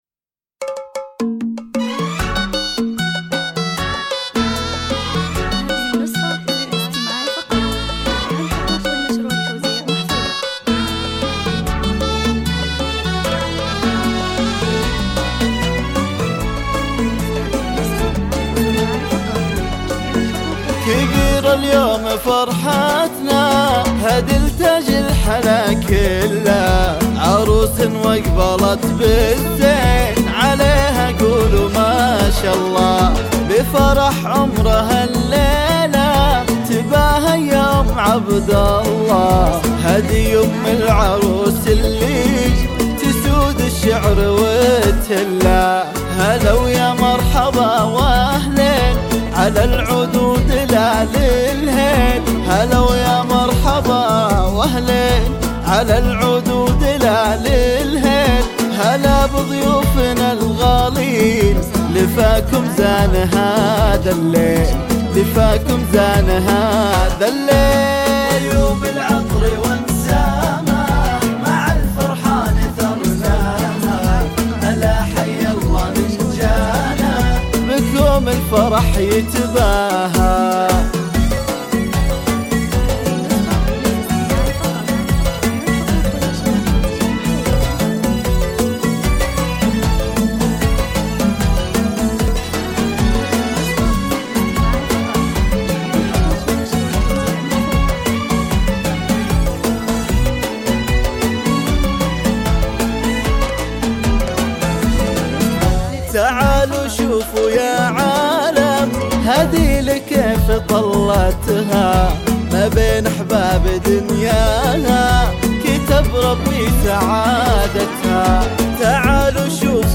زفة كوشة
مصممة خصيصًا لدخول أم العروس بطريقة ملكية ومبهرة